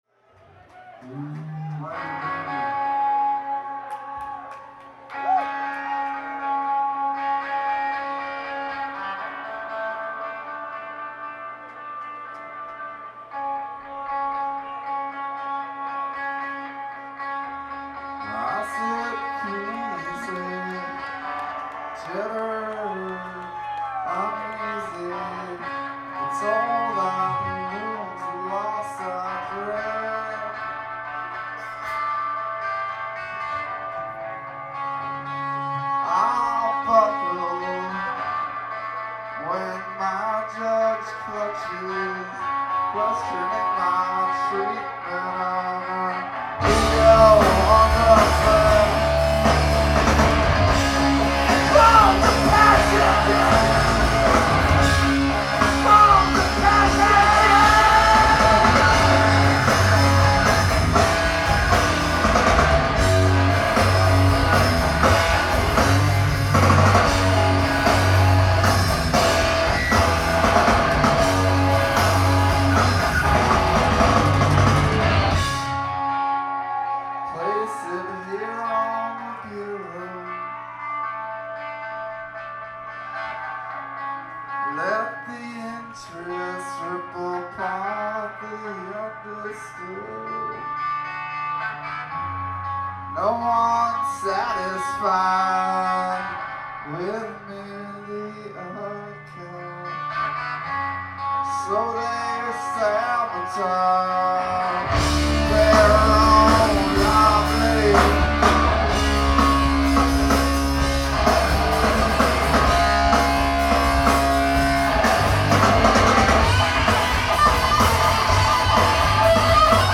live at Avalon